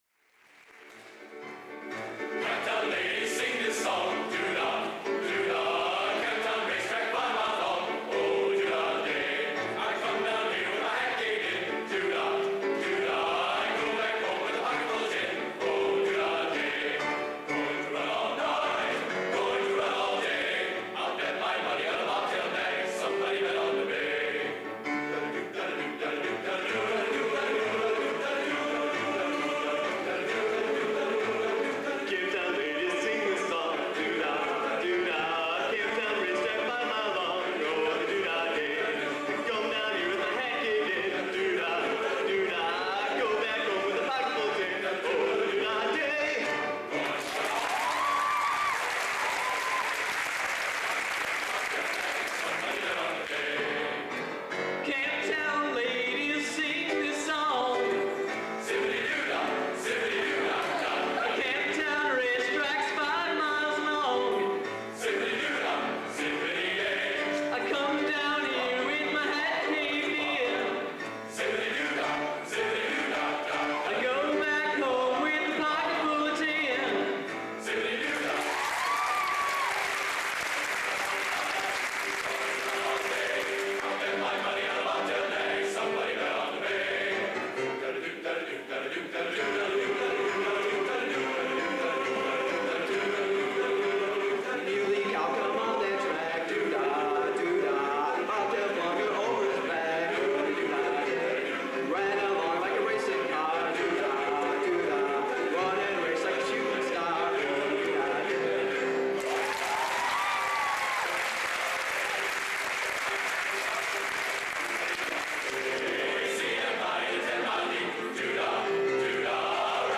Location: Purdue Memorial Union, West Lafayette, Indiana
Genre: Traditional | Type: